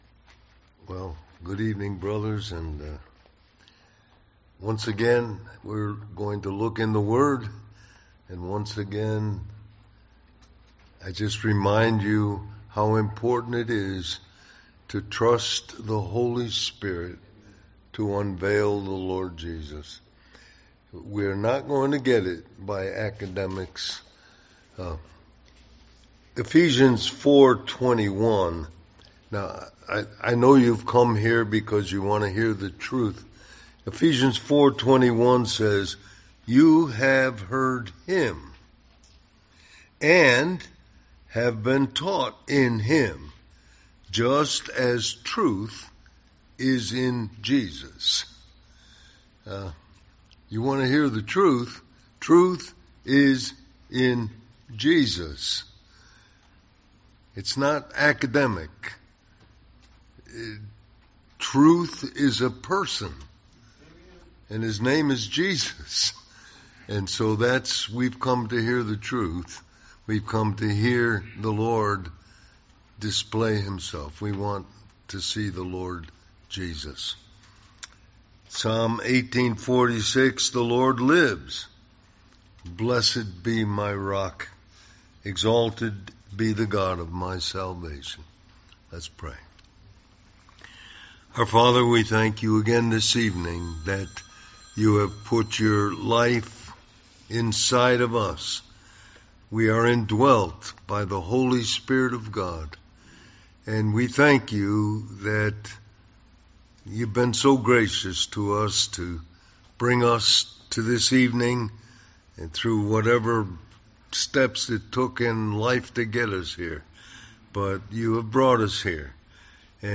The Living Rock Conference: 2026 Spring Men's Weekend